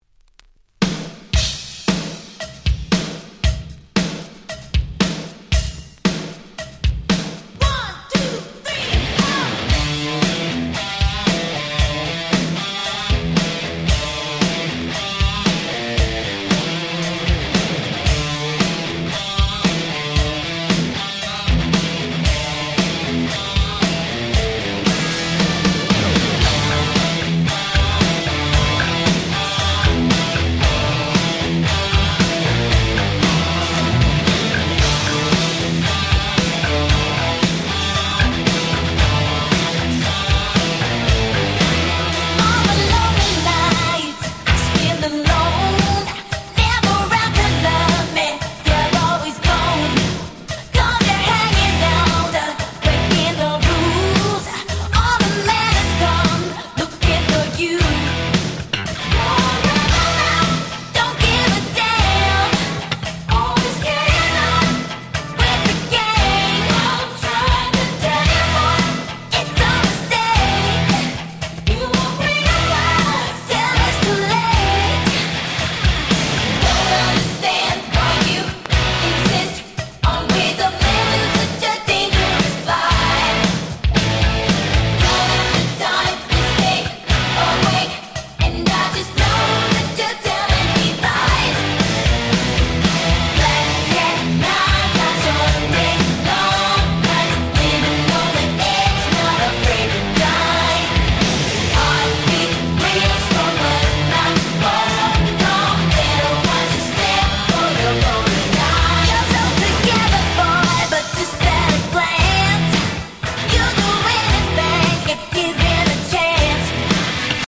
80's R&B Super Classics !!
New Jack Swing